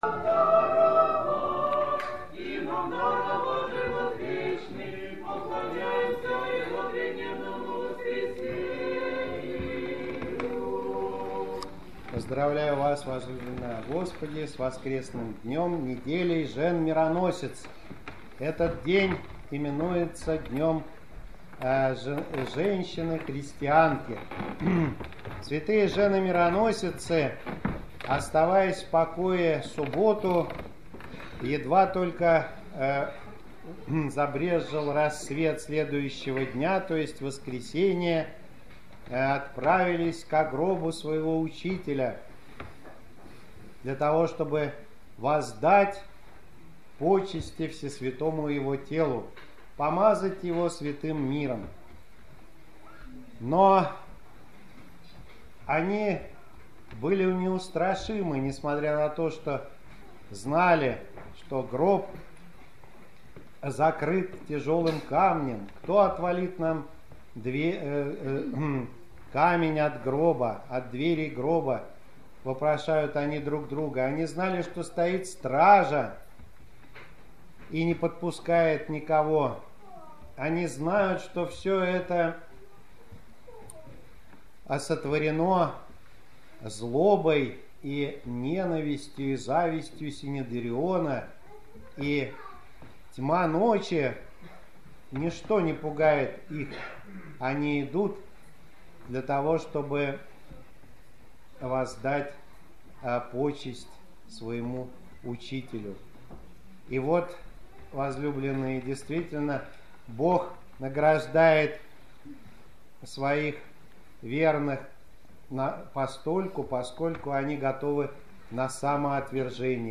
04.05.2014 Седмица 3-я по Пасхе - проповедь на литургия в память жен-мироносиц
Седмица 3-я по Пасхе - проповедь на литургия в память жен-мироносиц.MP3